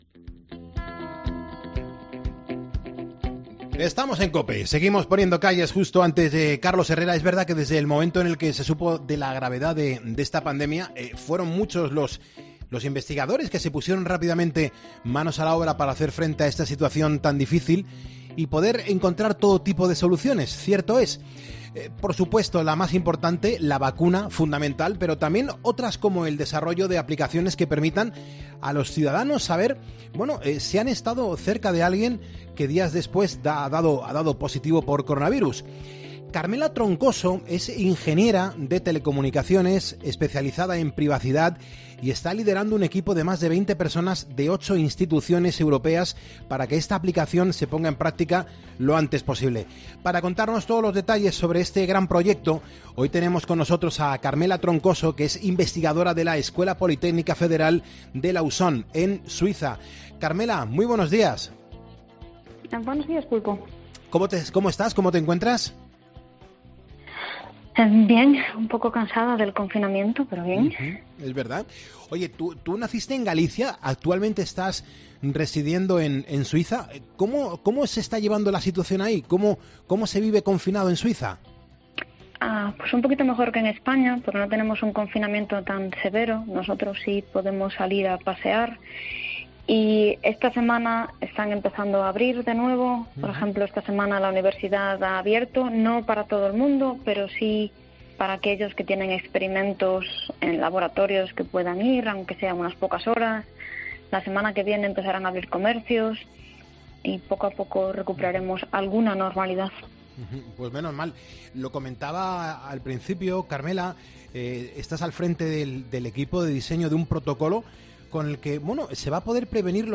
Para contarnos todos los detalles sobre este gran proyecto, hemos entrevistado, en Poniendo las Calles